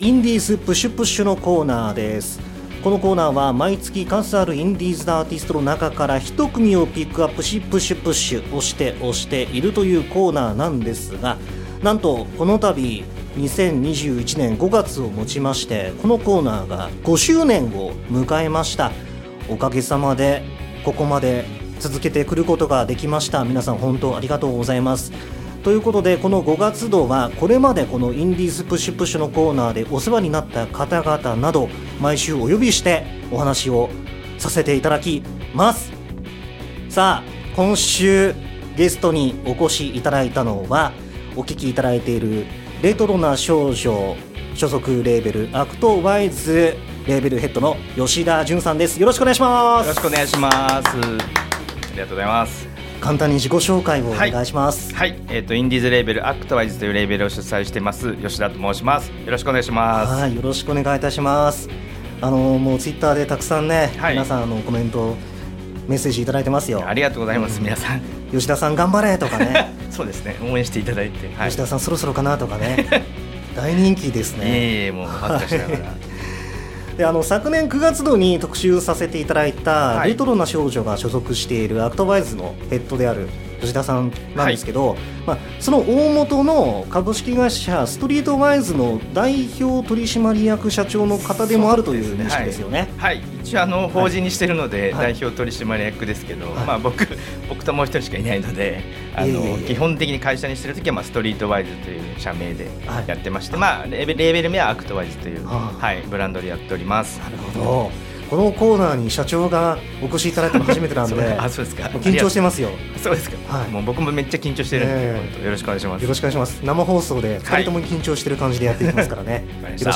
生放送の音源からトーク部分を抽出しWEB掲載用に編集しています。